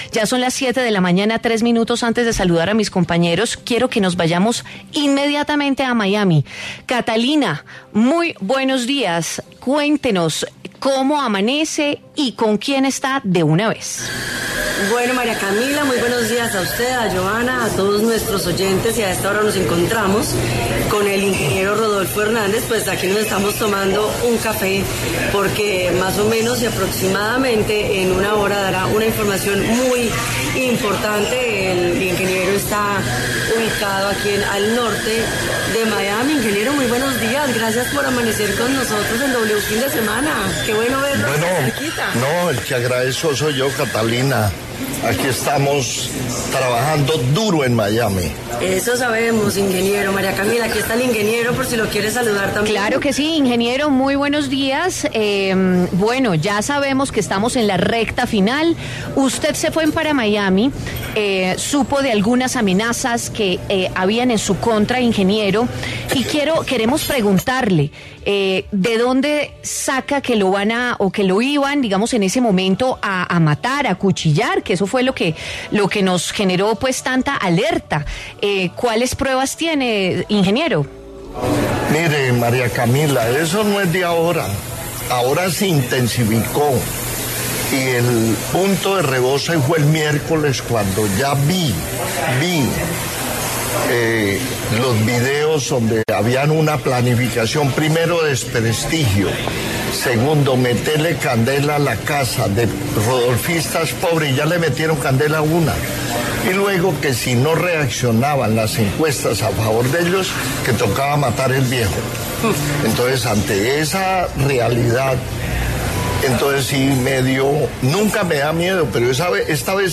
De cara a la segunda vuelta presidencial, el candidato Rodolfo Hernández pasó por los micrófonos de W Fin de Semana para hablar sobre el presunto atentado del que iba a ser víctima.